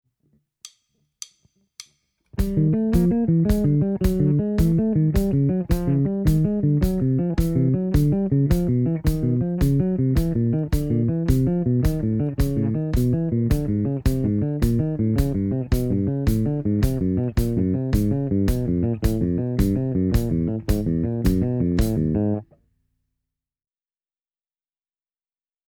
Voicing: Bass Guitar